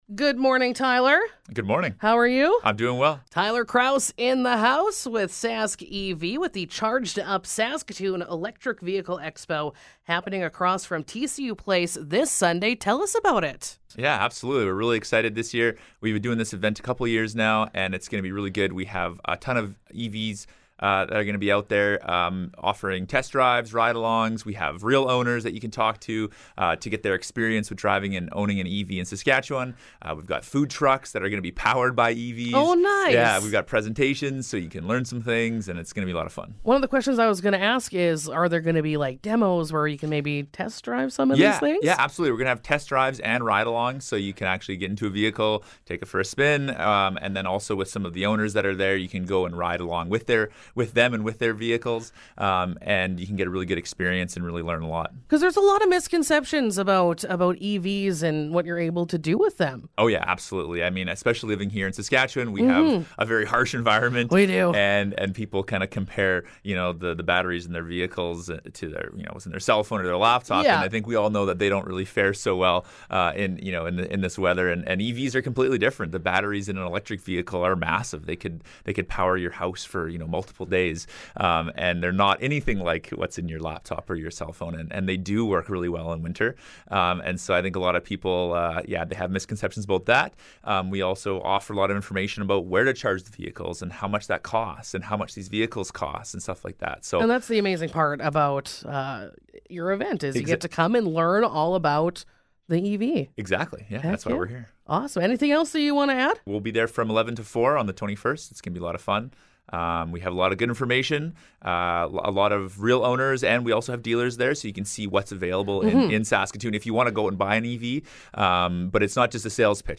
Interview: Charged Up – Saskatoon Electric Vehicle Expo